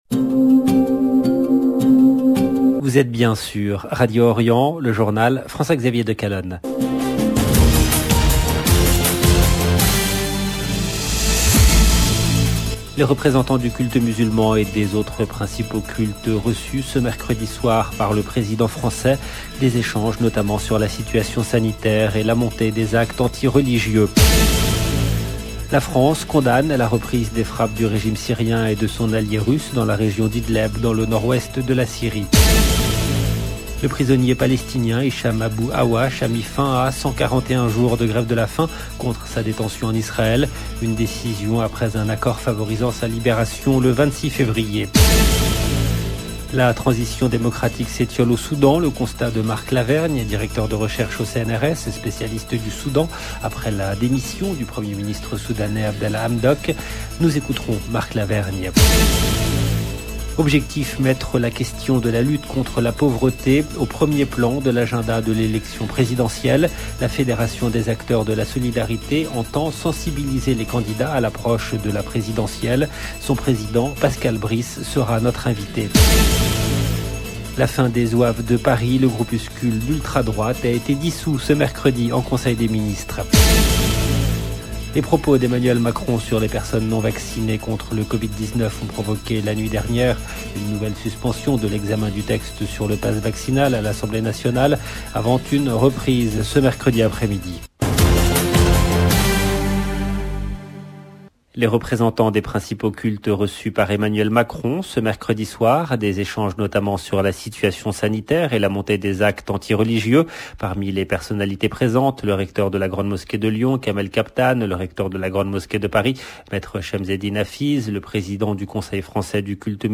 LE JOURNAL DU SOIR EN LANGUE FRANCAISE DU 05/01/22 LB JOURNAL EN LANGUE FRANÇAISE